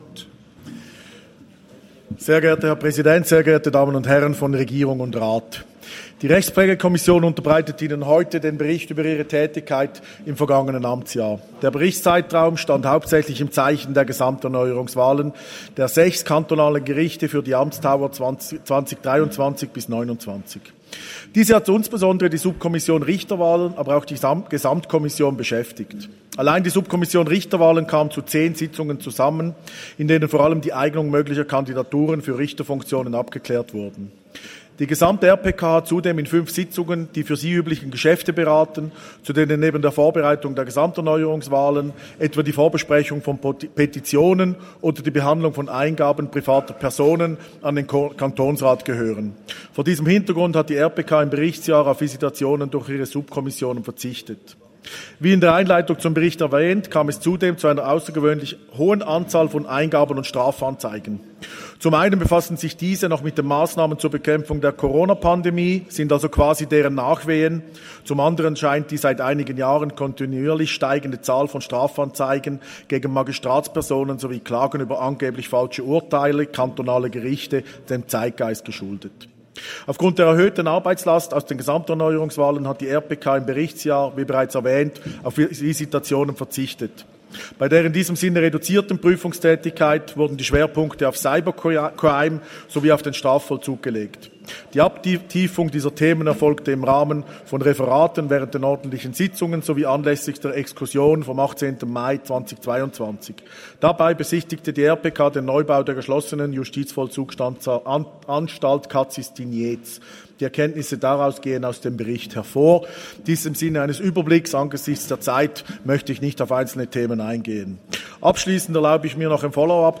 19.9.2022Wortmeldung
Session des Kantonsrates vom 19. bis 21. September 2022